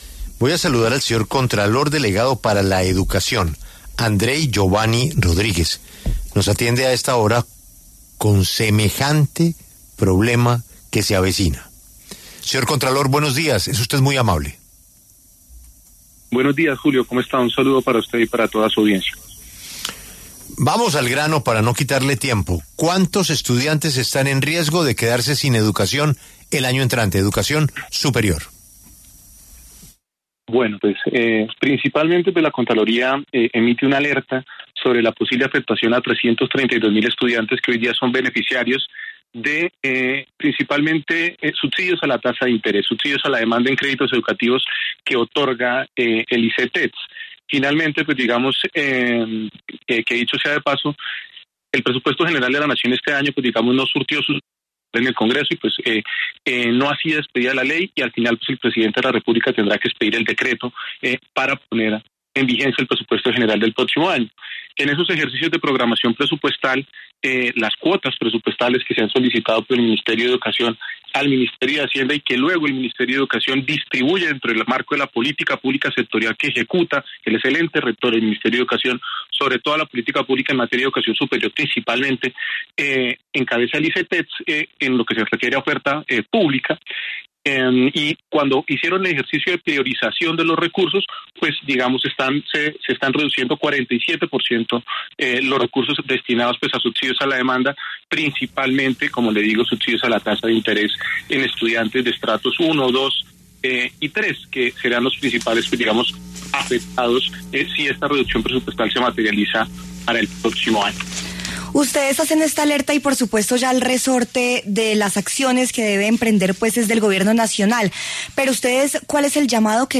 Para explicar a detalle el tema, pasó por los micrófonos de La W el contralor delegado para el sector de Educación, Ciencia y Tecnología, Cultura, Recreación y Deporte, Andrey Geovanny Rodríguez.